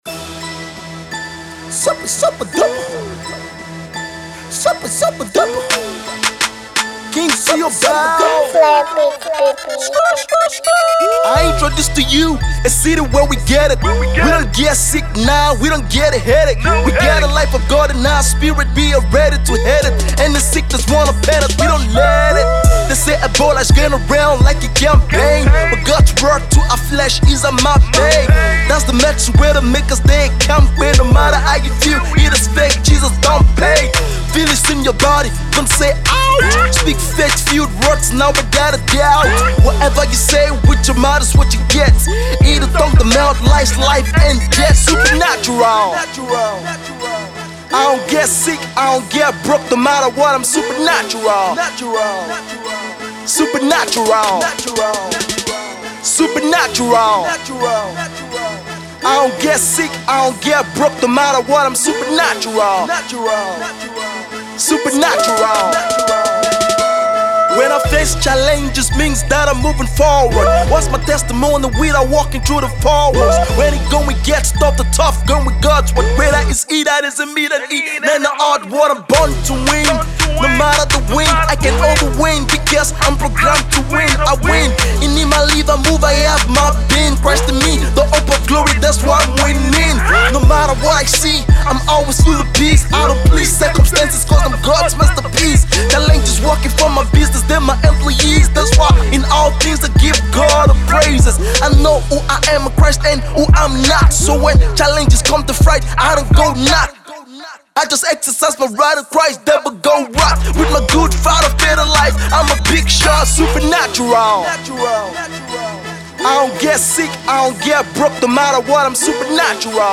Christian Hip-Hop
as he delivers inspiring verses